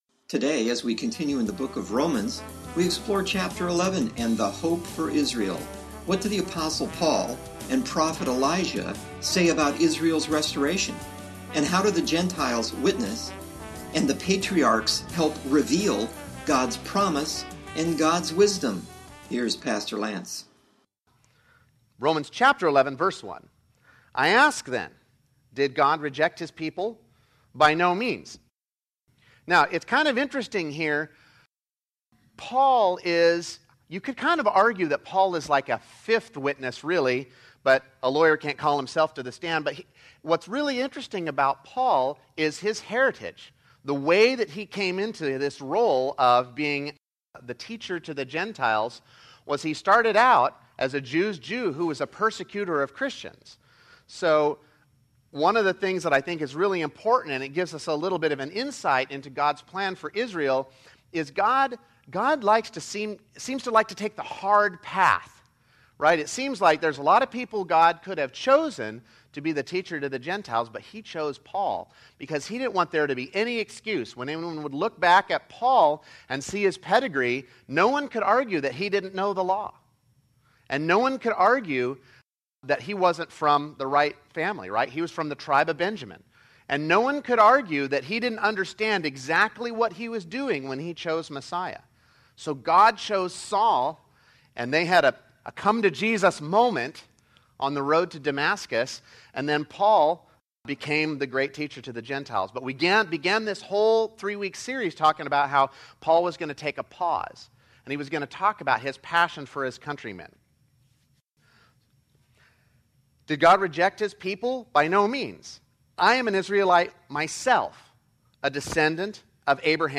08/30/15 Sermon – Churches in Irvine, CA – Pacific Church of Irvine